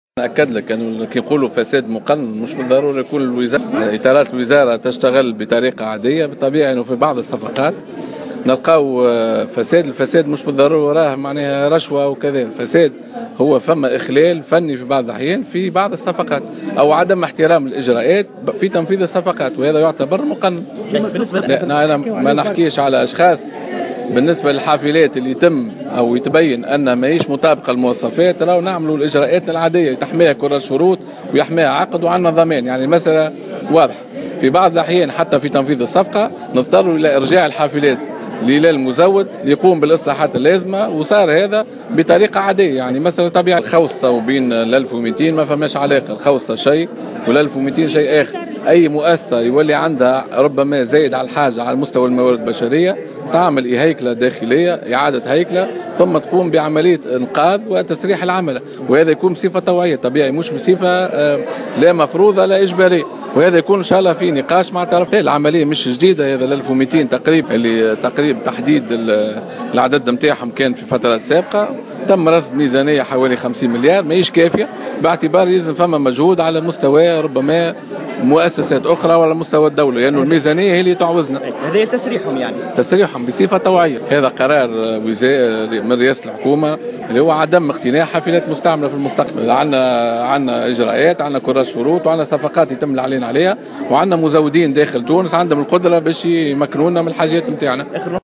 أكد وزير النقل رضوان عيارة في تصريح لمراسل الجوهرة "اف ام" أن المقصود بالفساد المقنن لبعض الصفقات العمومية لا يعني أن اطارات الوزارة لا تقوم بعملها بالشكل المطلوب معتبرا أن الفساد الموجود لا يتمثل في تسلم رشاوي بل يتعلق بإخلالات فنية شابت اتمام بعض الصفقات .